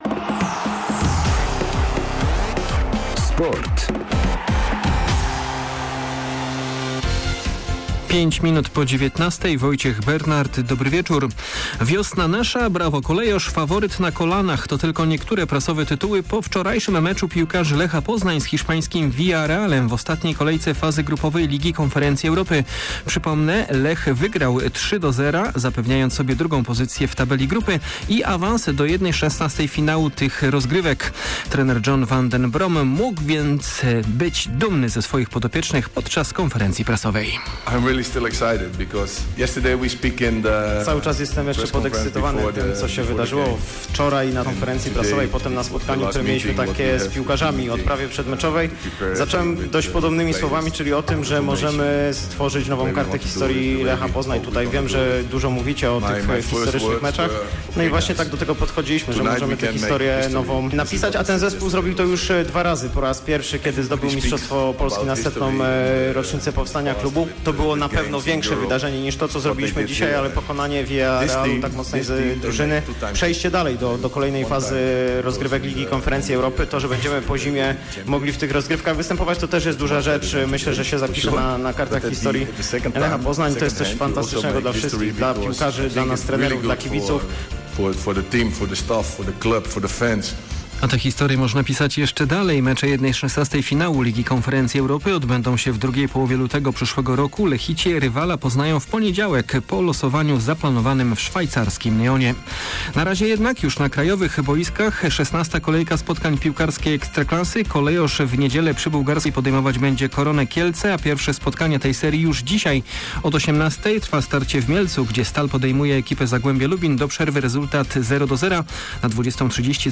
04.11.2022 SERWIS SPORTOWY GODZ. 19:05